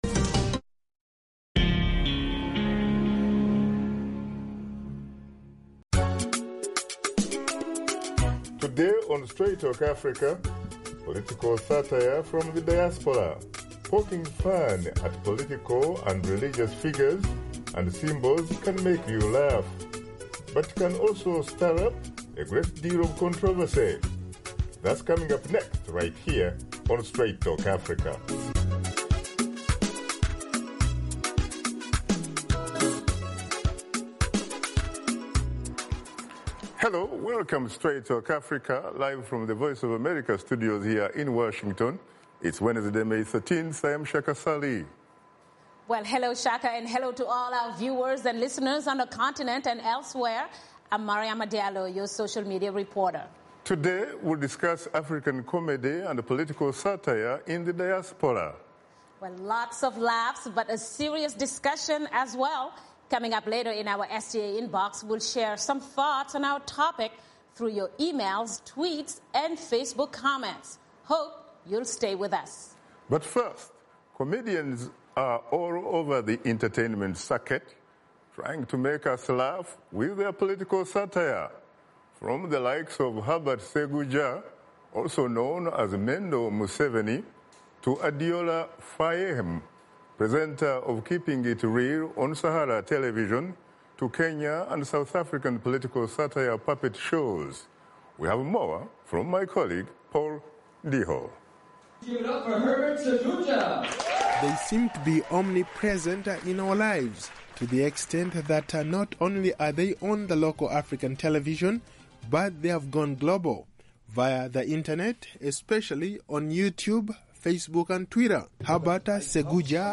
Host Shaka Ssali and his guests examine the state of political and religious satire on the African continent.